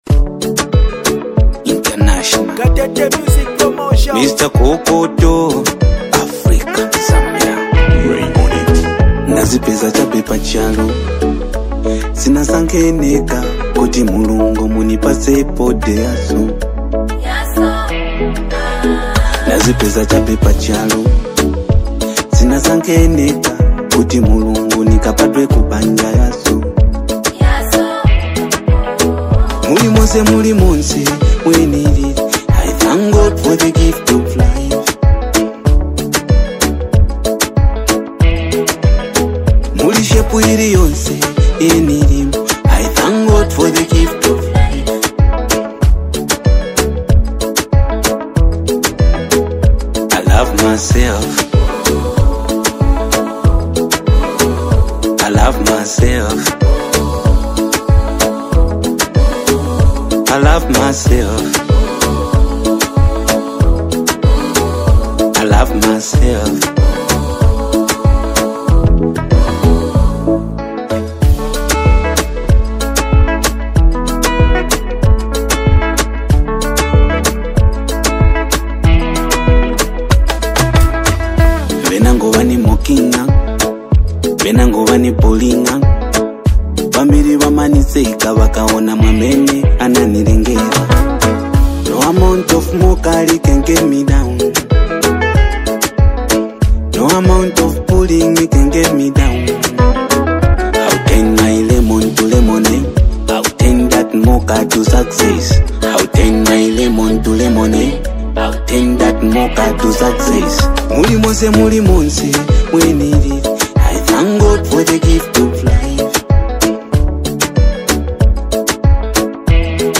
Genre: Afro Pop, Zambia Songs